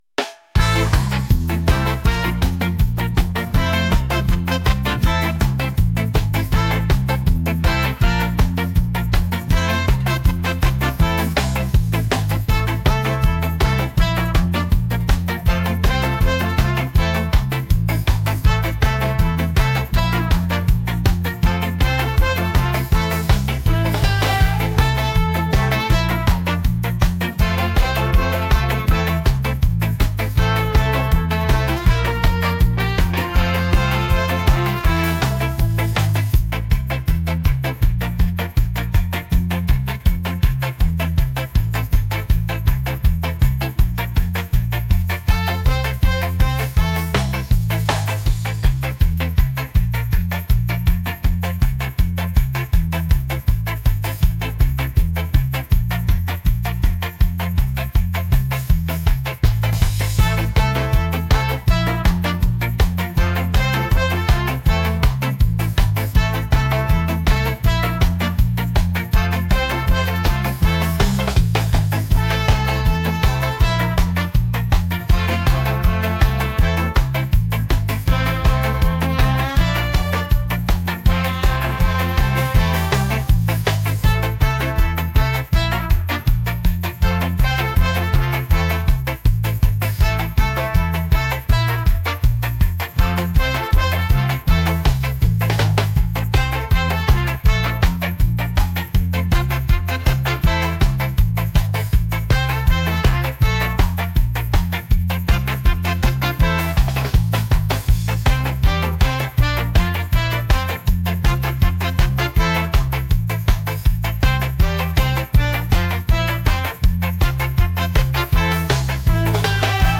reggae | upbeat | groovy